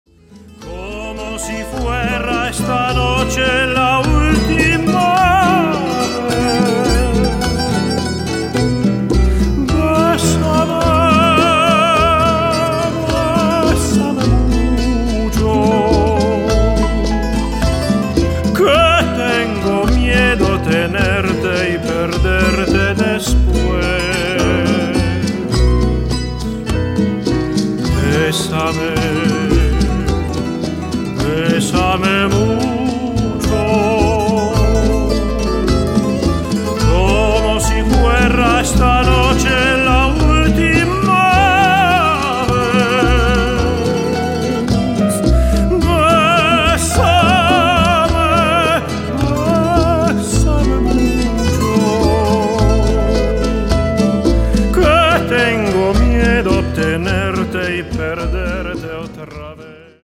Grandioser Gesang